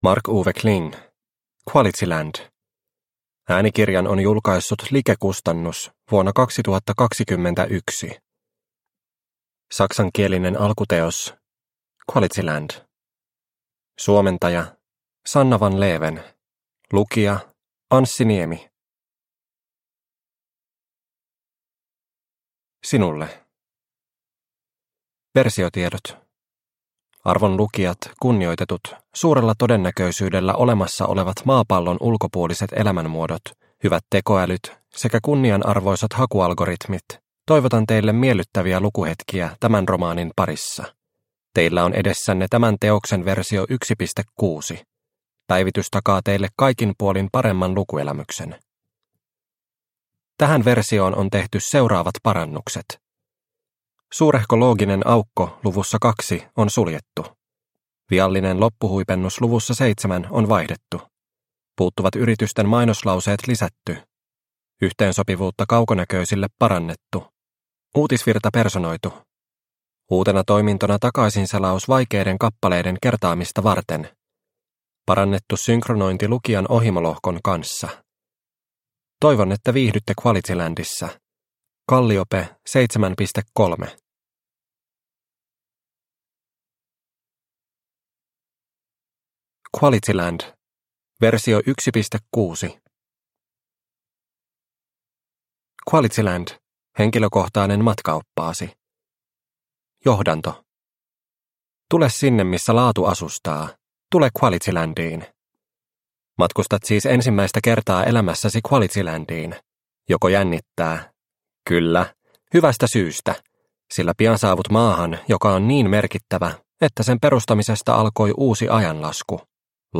QualityLand – Ljudbok – Laddas ner